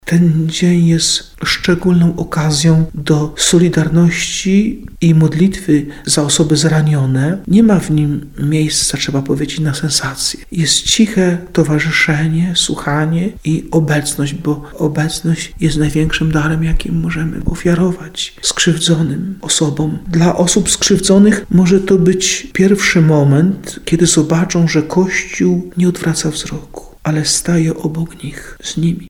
18ksiadz.mp3